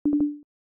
update-failed.ogg